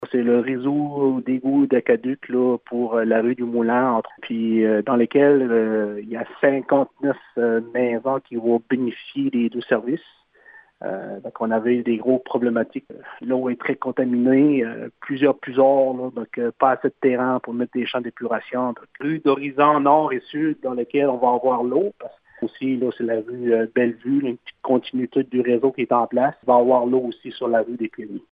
Le maire de Grande-Rivière, Gino Cyr, précise que 126 résidences  seront touchées par ces améliorations, qui dans certains cas étaient attendues depuis 20 ans :